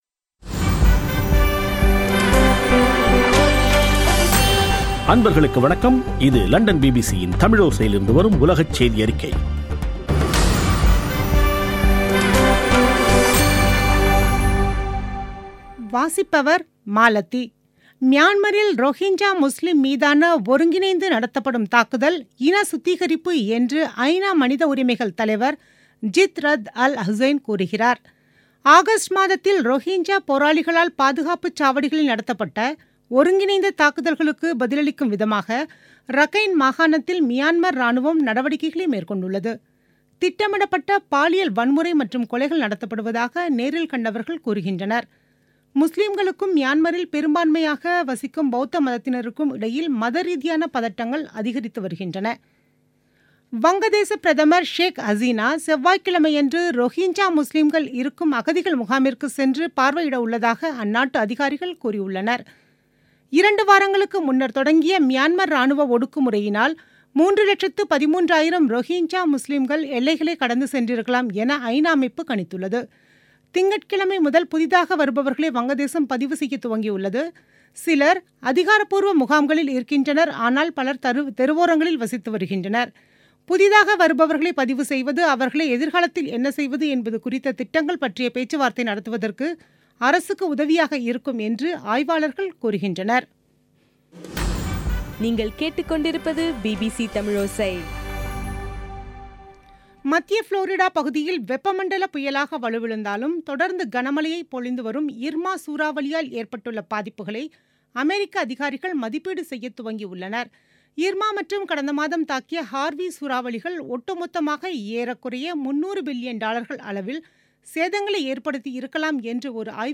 பிபிசி தமிழோசை செய்தியறிக்கை (11/09/2017